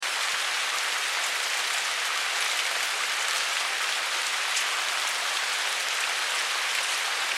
دانلود آهنگ طوفان 20 از افکت صوتی طبیعت و محیط
دانلود صدای طوفان 20 از ساعد نیوز با لینک مستقیم و کیفیت بالا
جلوه های صوتی